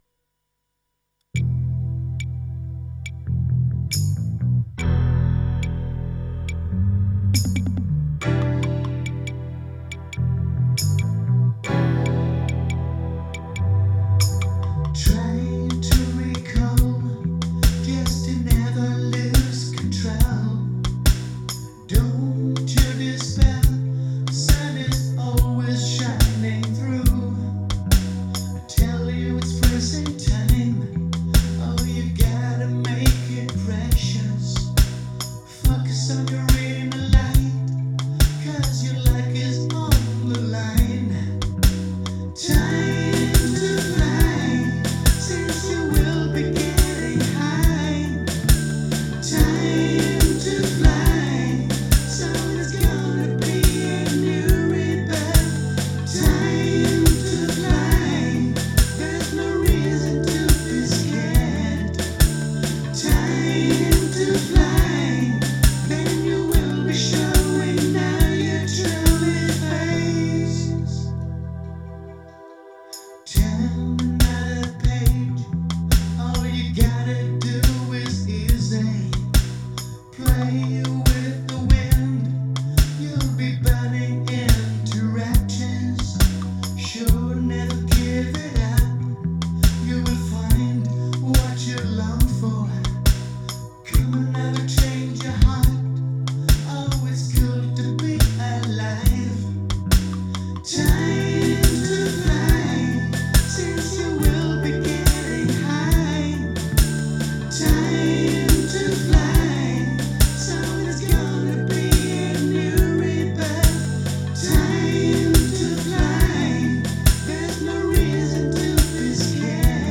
Genre : Brit. Pop.